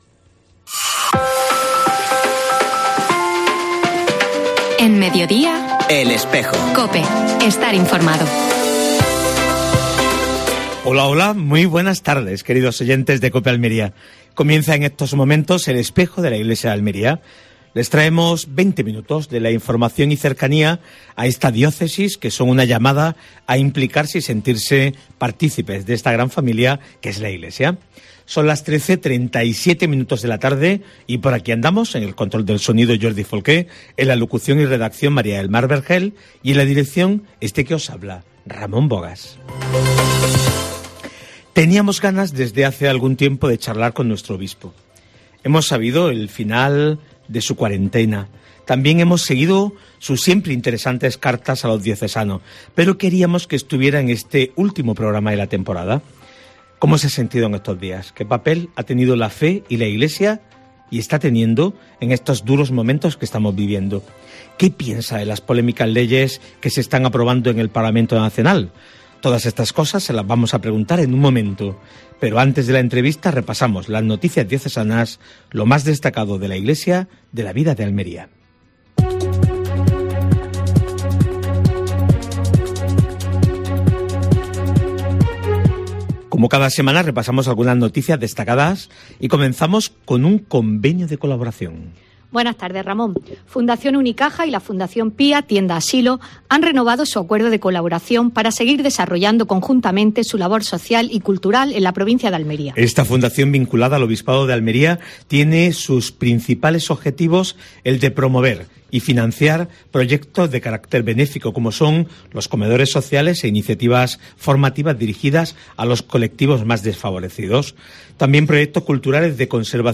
AUDIO: Actualidad de la Iglesia en Almería. Entrevista al Obispo de la Diócesis almeriense (Monseñor Adolfo González Montes).